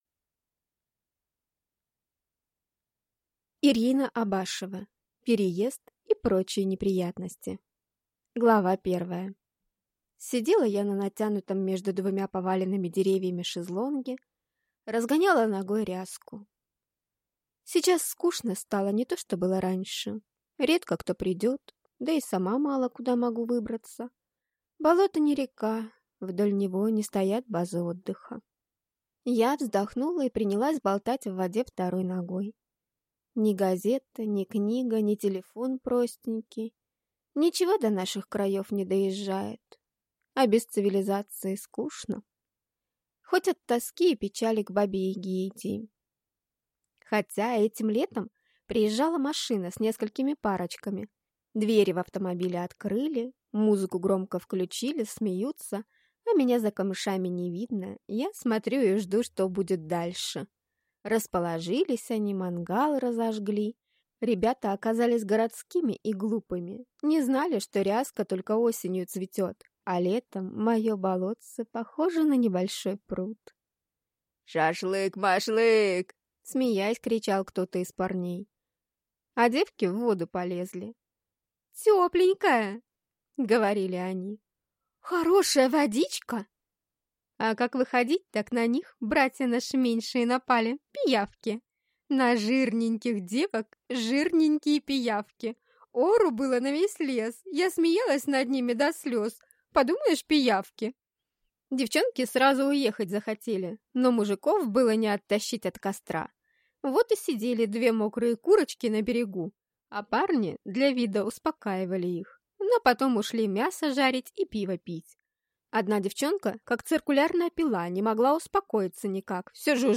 Аудиокнига Переезд и прочие неприятности | Библиотека аудиокниг